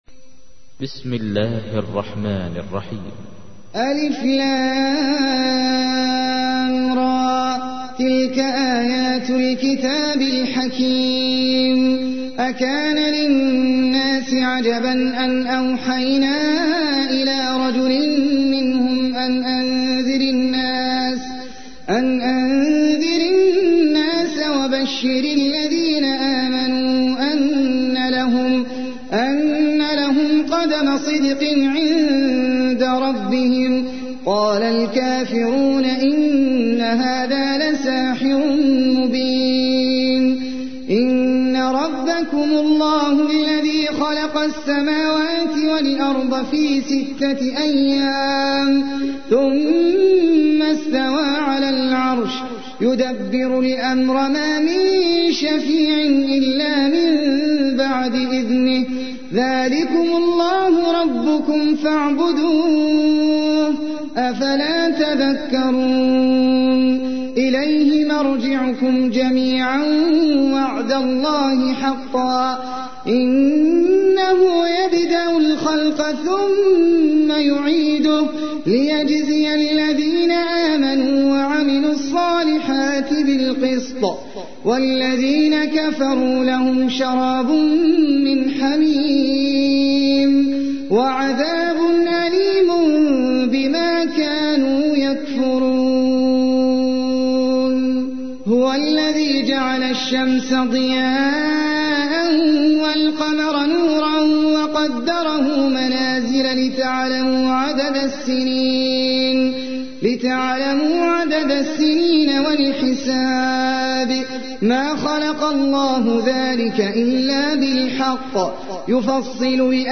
تحميل : 10. سورة يونس / القارئ احمد العجمي / القرآن الكريم / موقع يا حسين